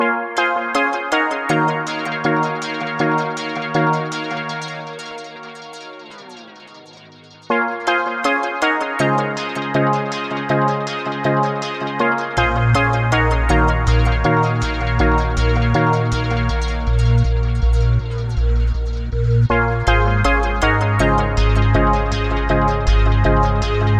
Tag: 80 bpm Chill Out Loops Synth Loops 2.02 MB wav Key : A